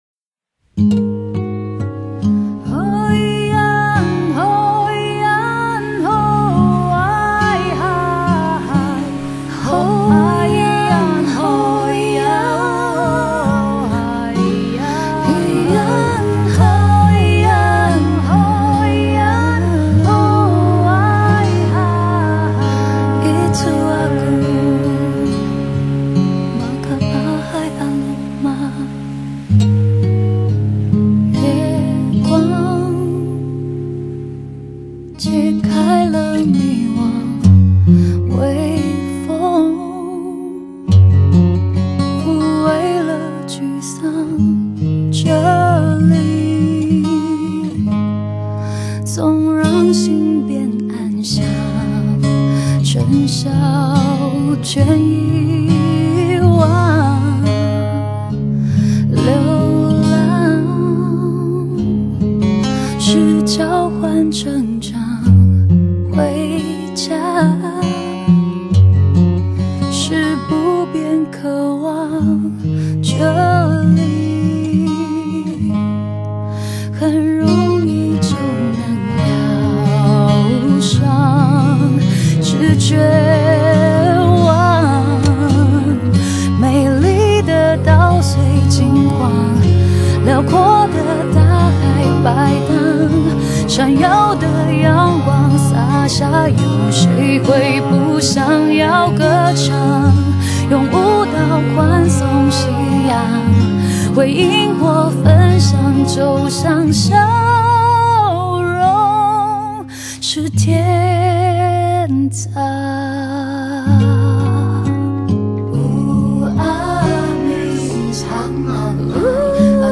台灣原住民籍女歌手